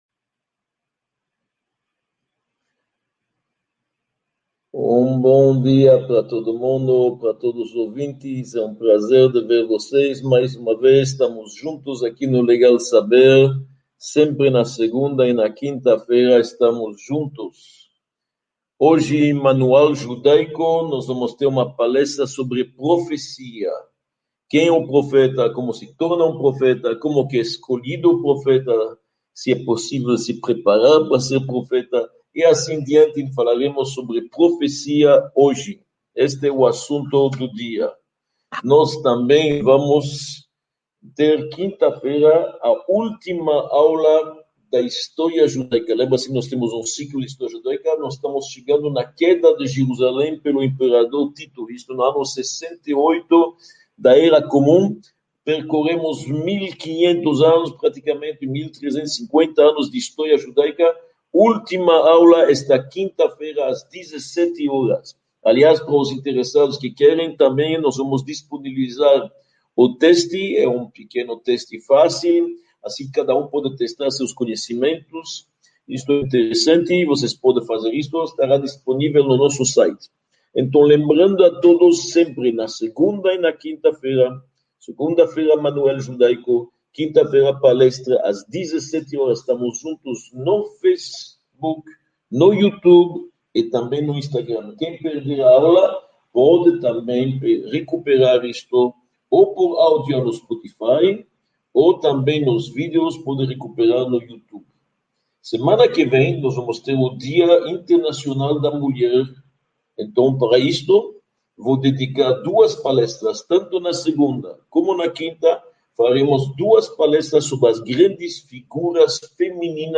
22 – Profecia | Módulo I – Aula 22 | Manual Judaico